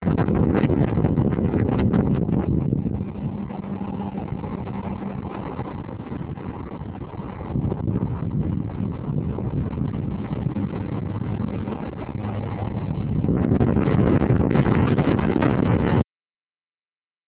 東名を走るＴＲ４（ﾘｱﾙﾌﾟﾚｰﾔｰ）